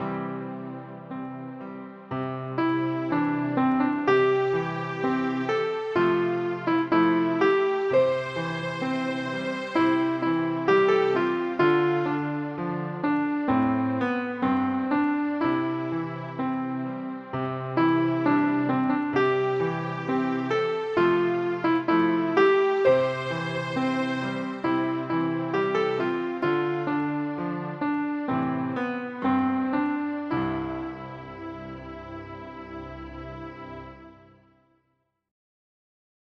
Audio Midi Bè 2: download